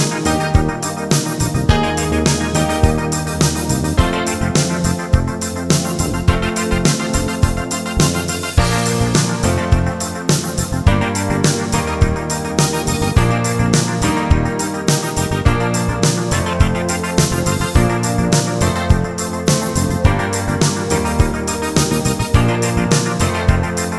End Cut Down Pop (1980s) 4:22 Buy £1.50